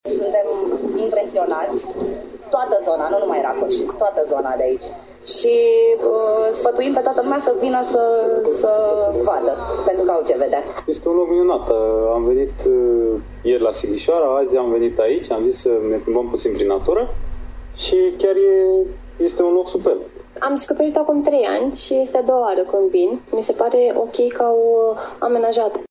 VOXURI-LAC-smarald.mp3